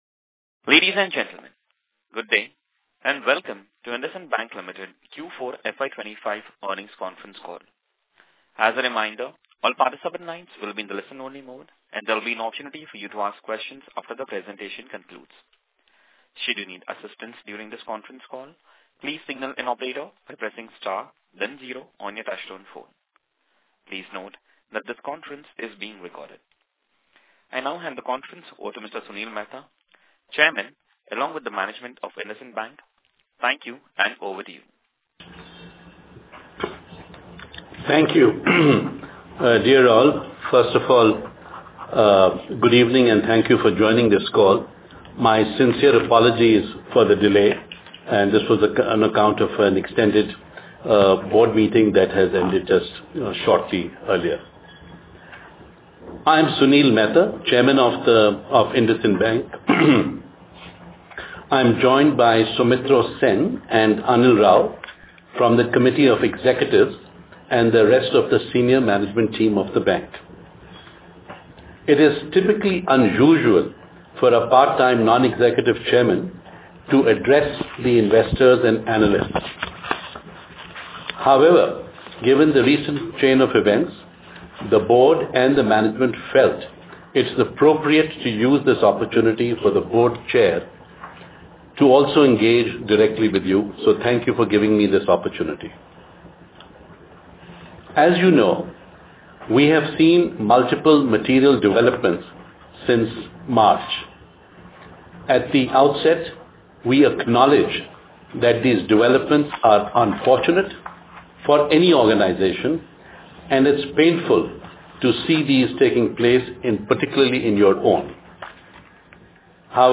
Audio Recording of Earnings Call